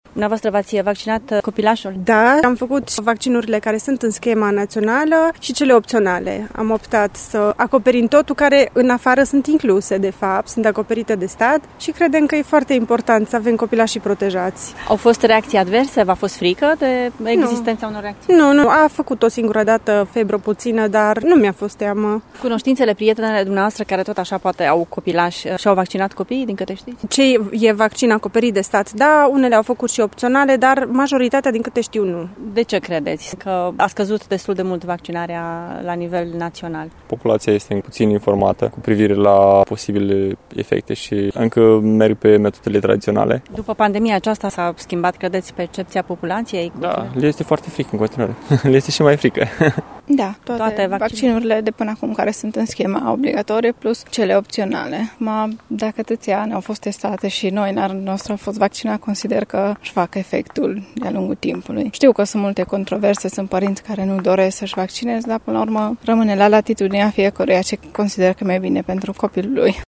Părinții tineri din Târgu Mureș își imunizează în general copiii, inclusiv cu vaccinurile opționale și cred că pandemia a acutizat teama de reacții adverse: